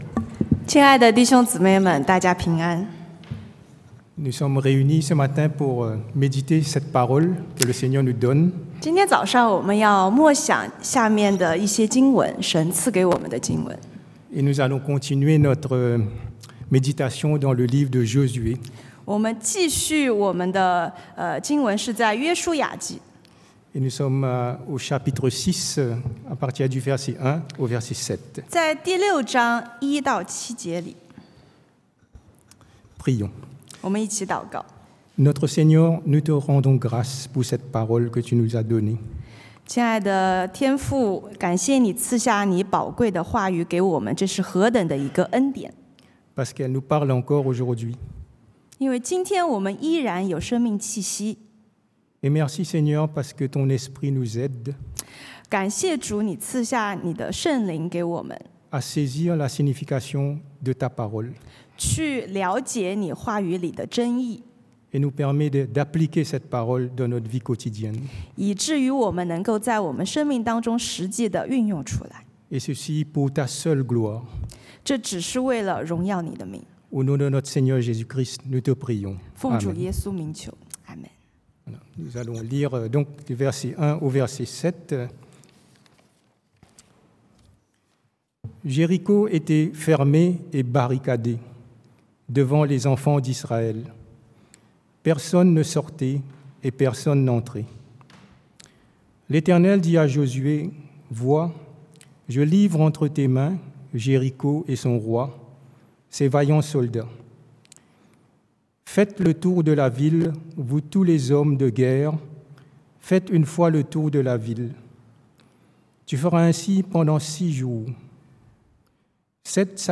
Passage: Josué 约书亚记 6 : 1-7 Type De Service: Predication du dimanche « Jonas à Ninive 约拿在尼尼微 Grâce offerte